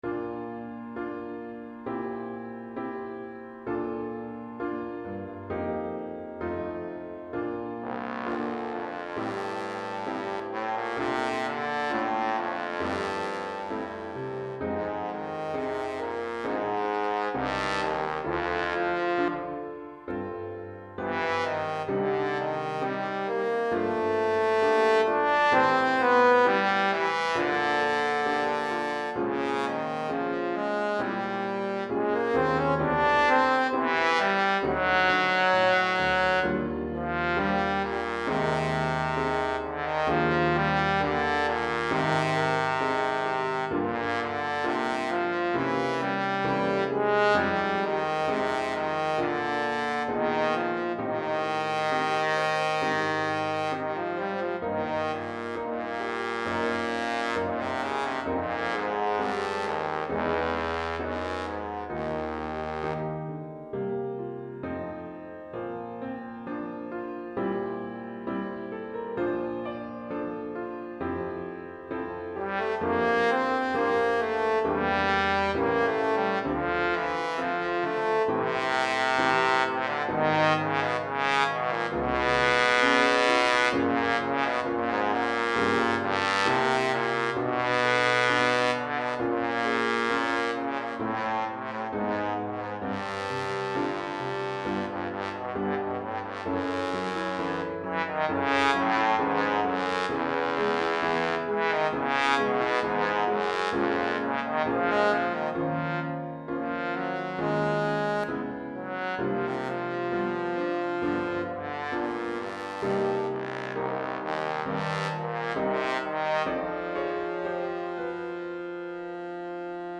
Répertoire pour Trombone - Trombone Basse et Piano